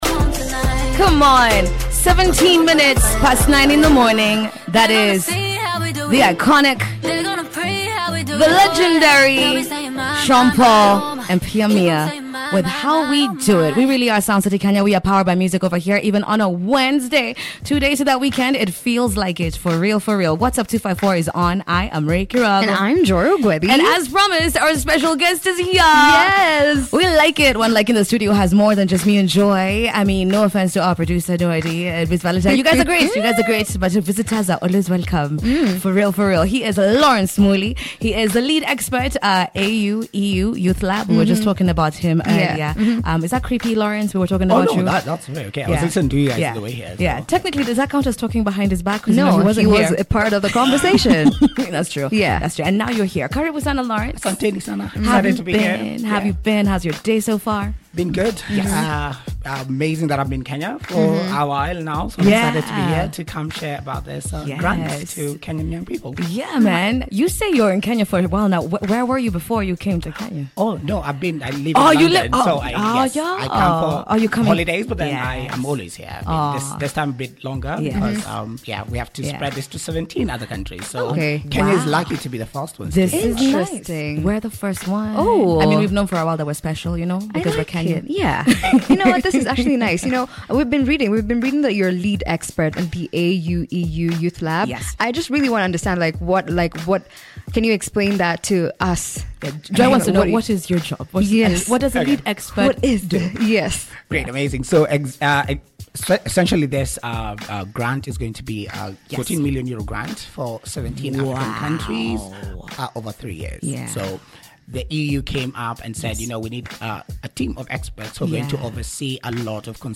INTERVIEW-LINK-1.mp3